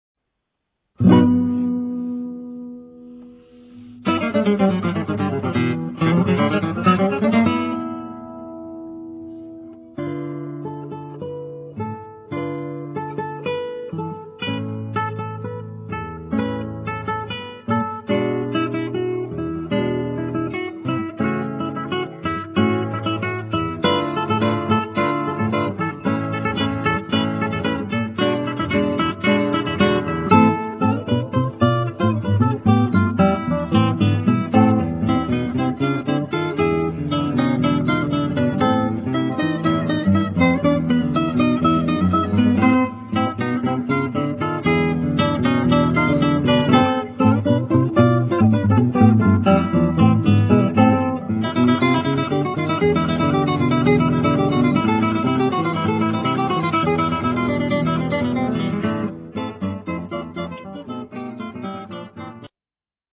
DÚO DE GUITARRAS